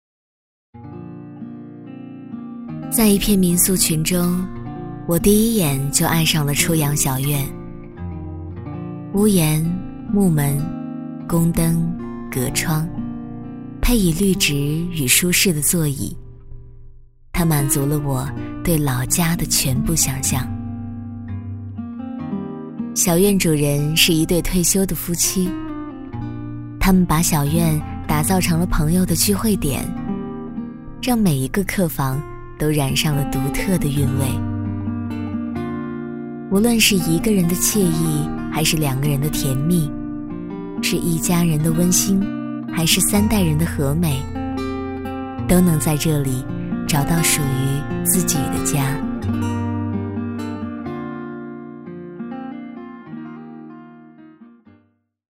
女国132_其他_旁白_民宿旁白.mp3